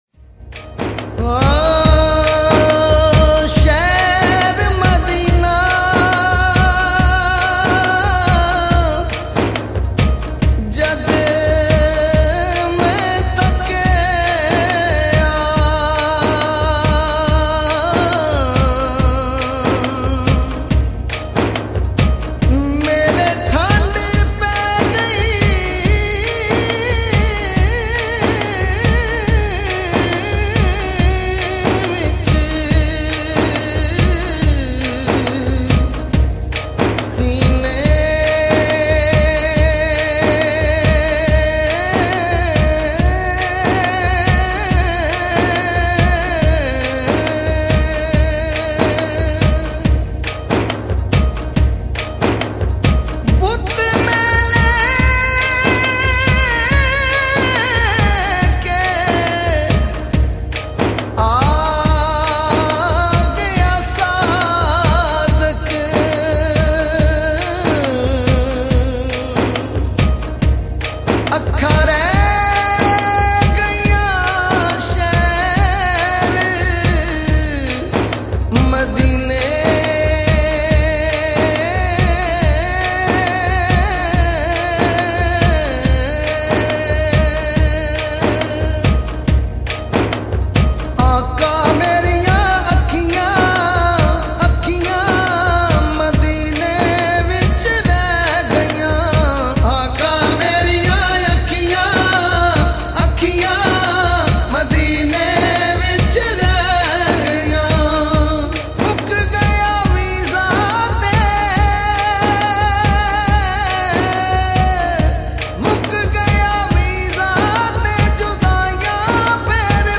Naat,, AAQA Meriyan Akhiyan ,,,